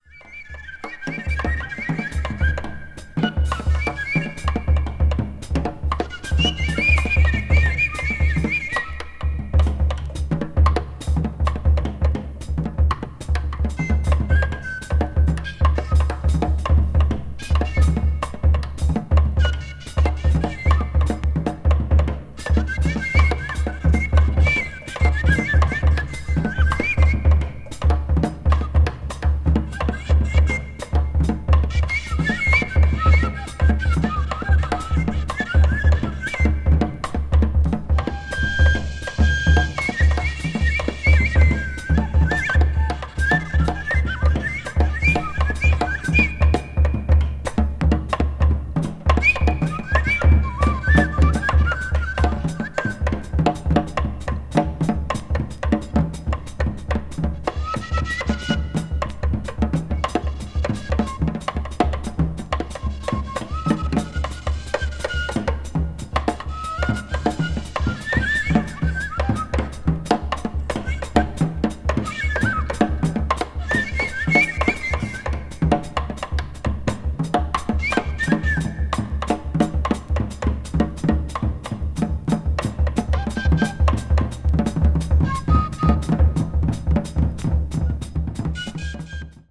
media : EX+/EX+(some slightly noises.)